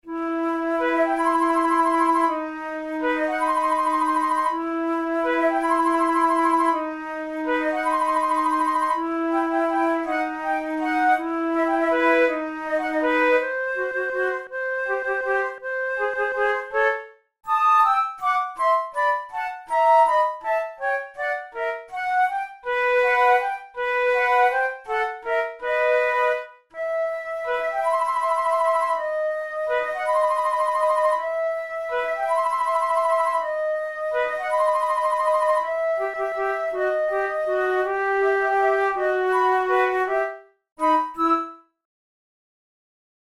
InstrumentationFlute duet
KeyE minor
Time signature3/4
Tempo54 BPM
Contemporary, Fanfares, Written for Flute